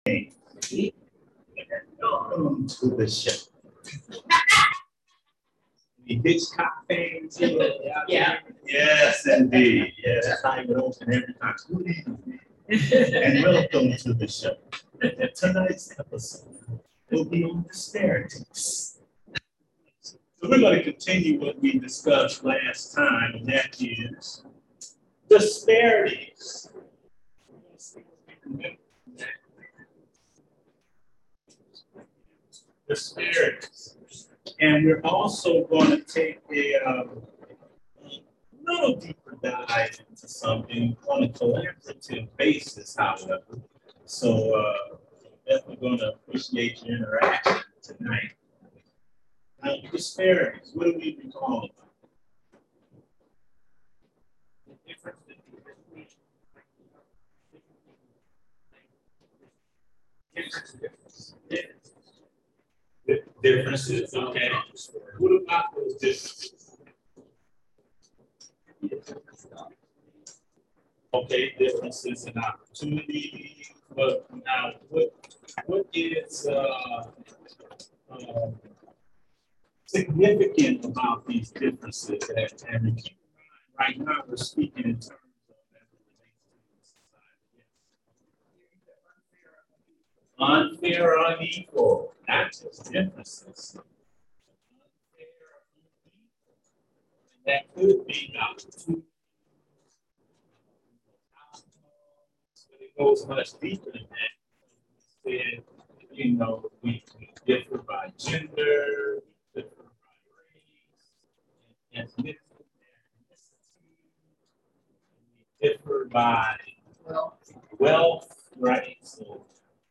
Bible Study - New Life Community Church